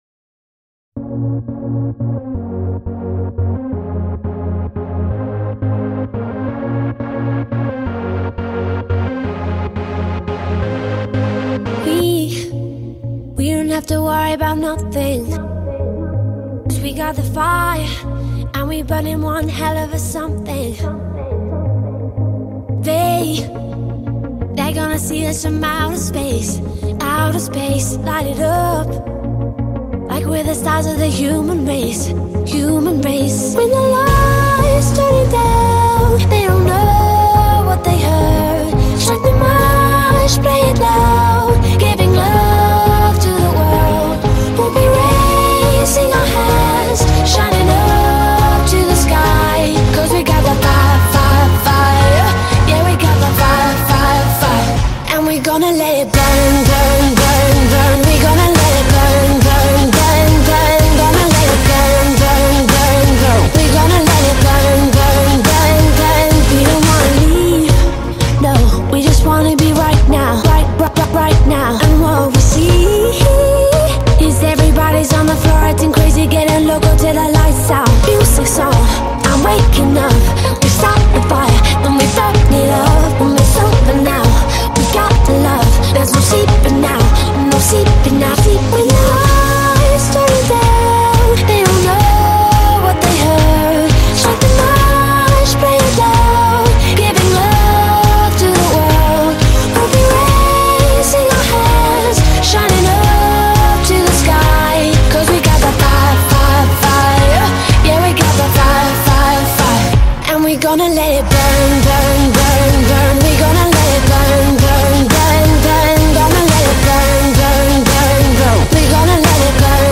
angelic, breathy delivery